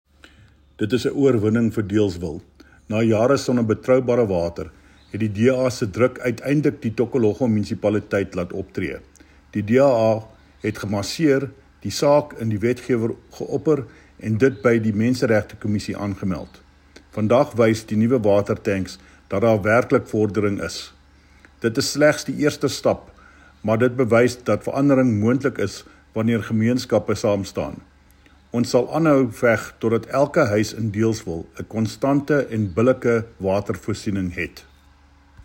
Afrikaans soundbite by David Mc Kay MPL.